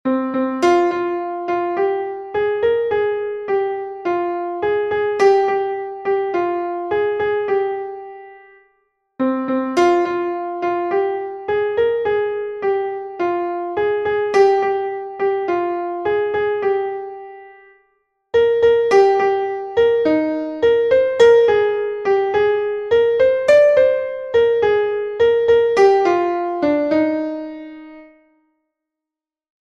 - Exercise 1: C minor (bars 1 to 8) and E♭ major (bars 9 to 16).
melodic_reading_3.mp3